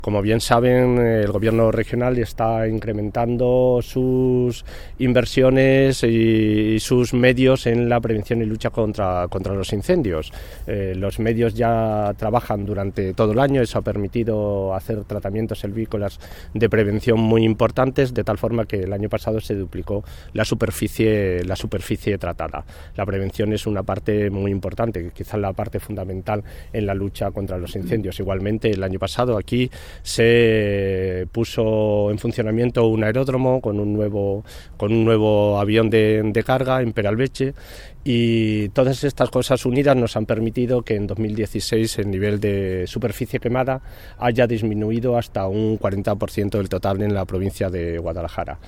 El viceconsejero de Medio Ambiente, Agapito Portillo, habla de las labores de prevención de incendios llevadas a cabo por el Gobierno regional en la provincia de Guadalajara